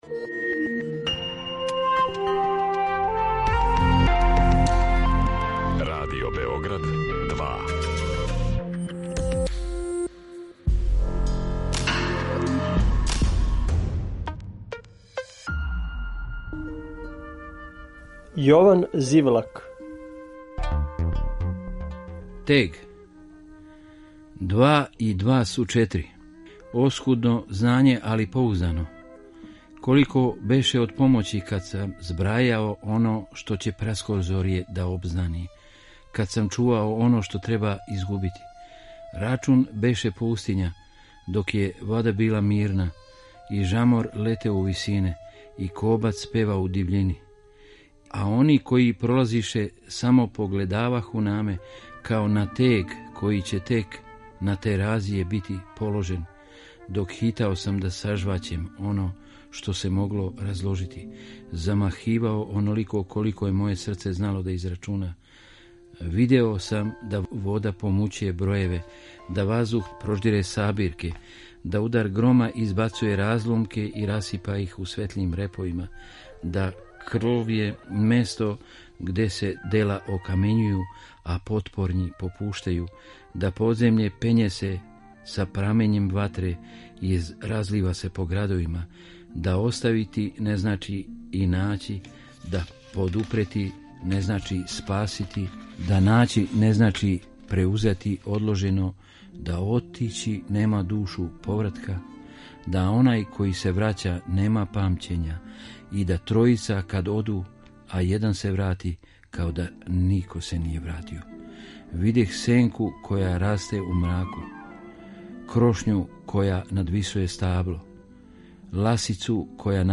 Можете чути како своје стихове говори песник